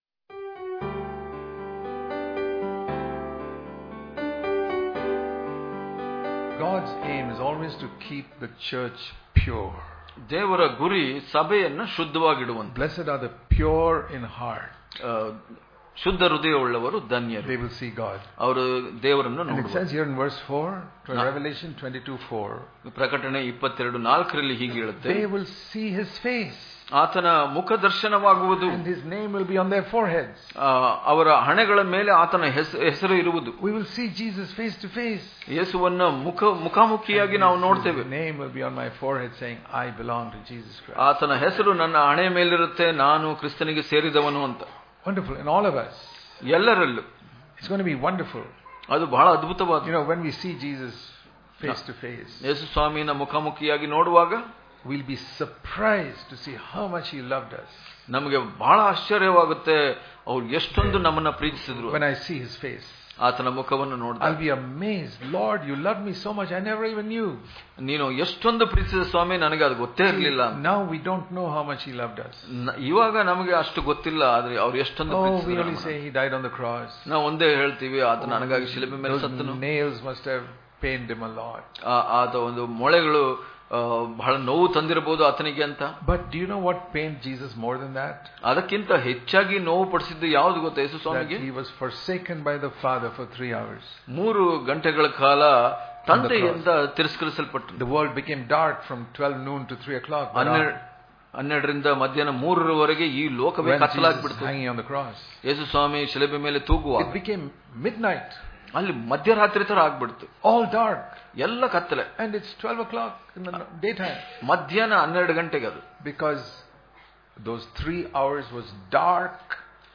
March 12 | Kannada Daily Devotion | Knowing How Much Jesus Loved Us Daily Devotions